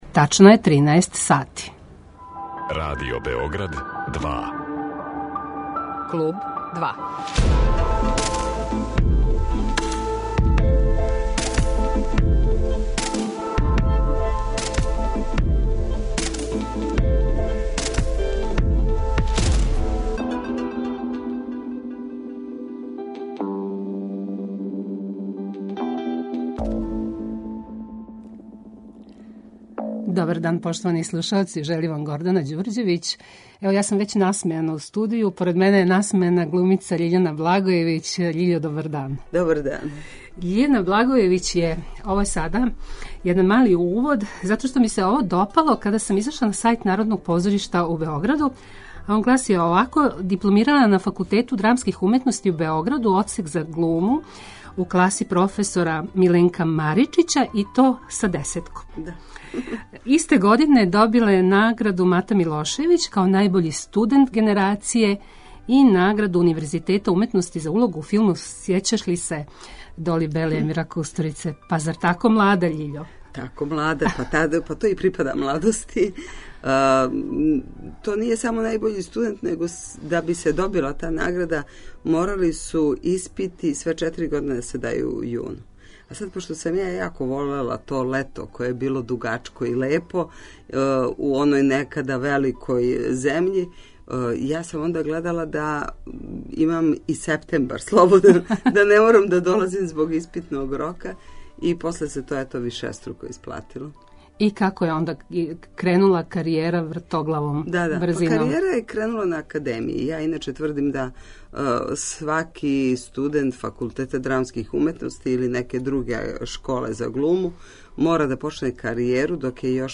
Гост 'Клуба 2' је глумица Љиљана Благојевић.